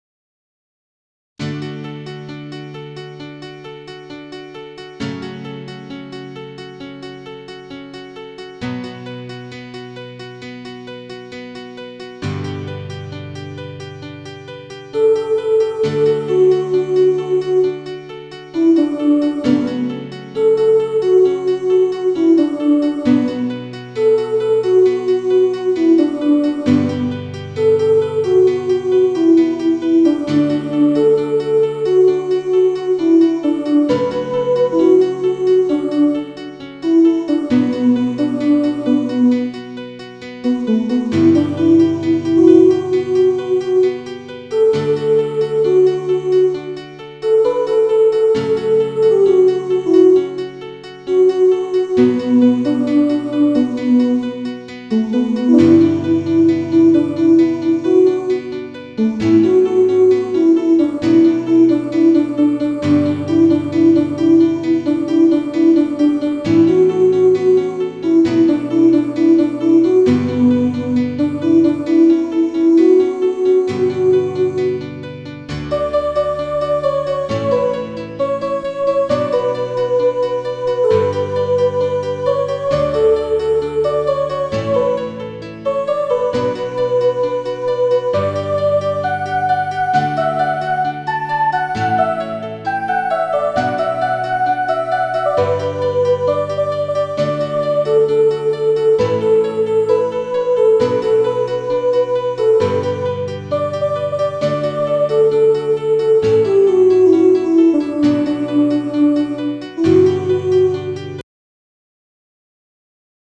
Tenor (high voice) Track